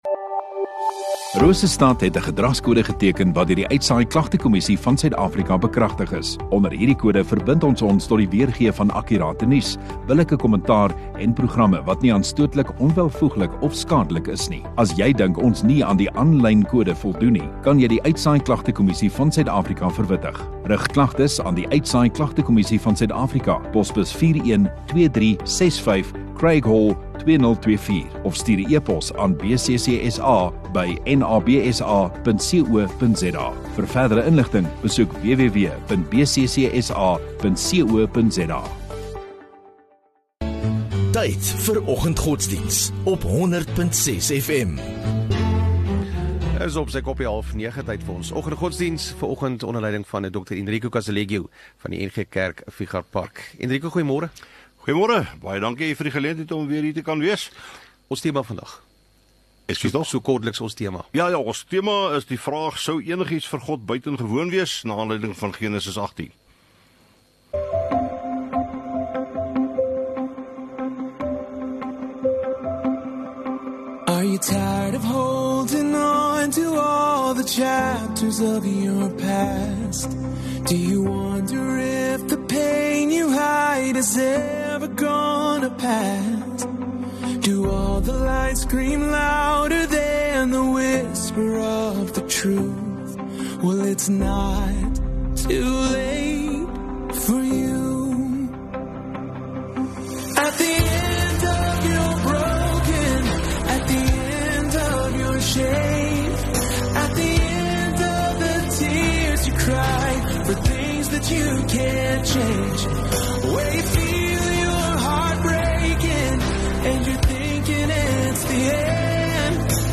25 Jul Donderdag Oggenddiens